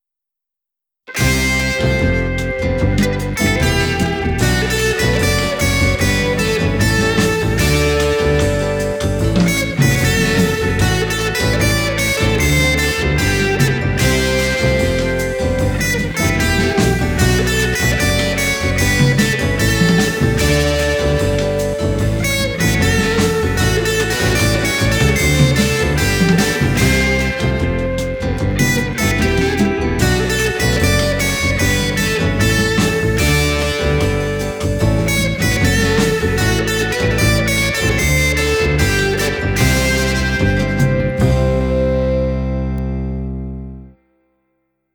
C…|F…|G…|Em…|Bm…|F#m…|G…|A7…|
D…|G…|A…|F#m…|A…|A7…|D7…|G7….|C…
Let’s apply a Latin, Santana-ish feel, and just see where the chords take me …